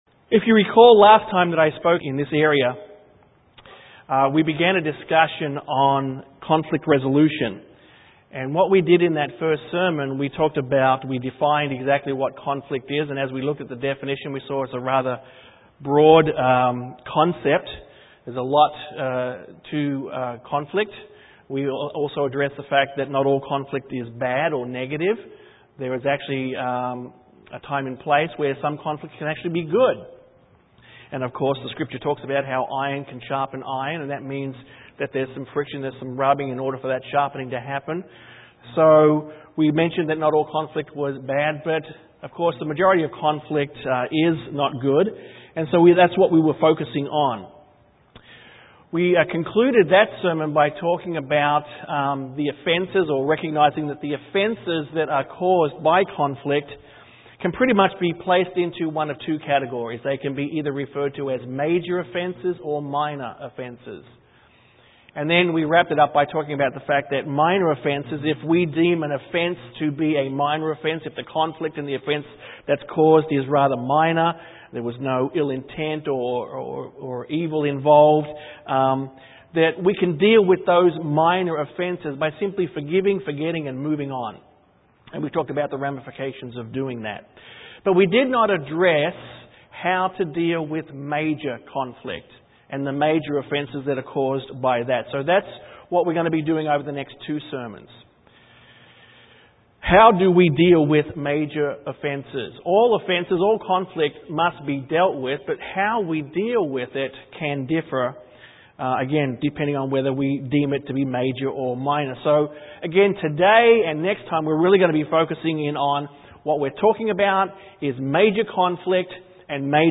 This sermon is part 2 in a 3 part series. Conflict, whether minor or major is a part of the human experience and as Christians it is important that we know how, and how not to deal with it. This sermon focused on how to deal with major conflict.